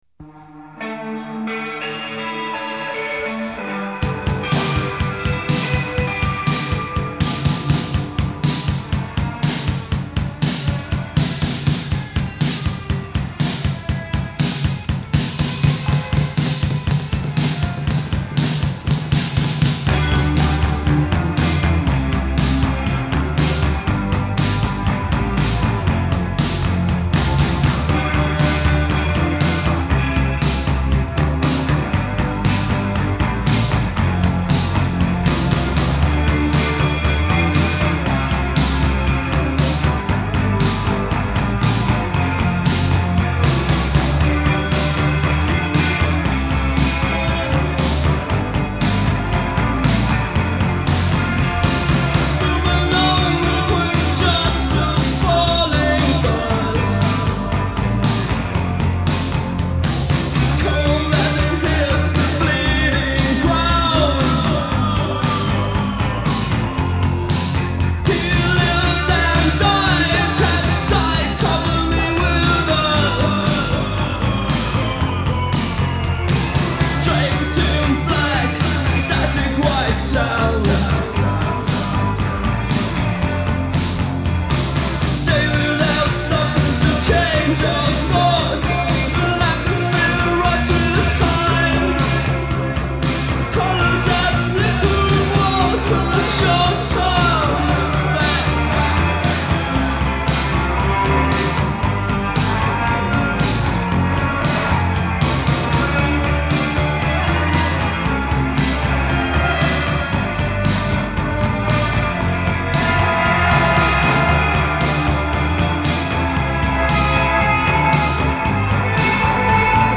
Пост панк Рок